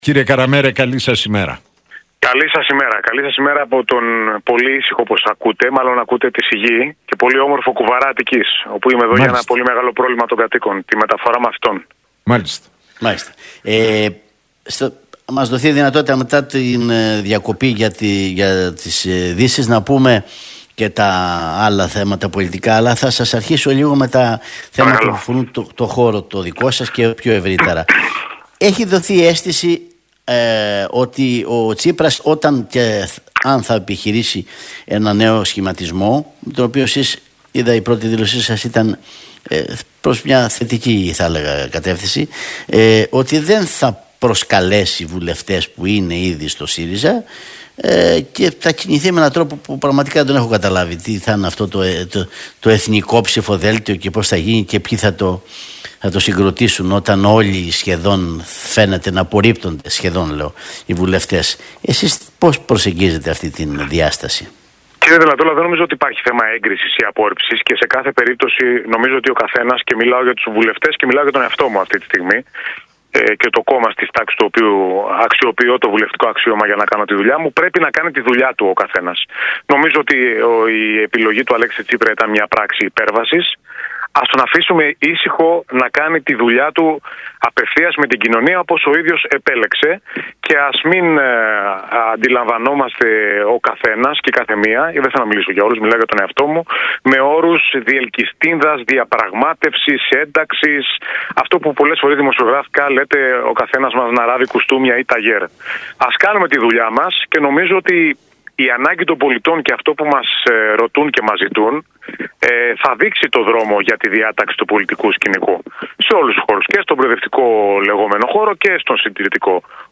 Ο Γιώργος Καραμέρος, εκπρόσωπος Τύπου του ΣΥΡΙΖΑ, μίλησε στον Realfm 97,8 για τον Αλέξη Τσίπρα, χαρακτηρίζοντάς τον ως τον φυσικό ηγέτη του προοδευτικού χώρου.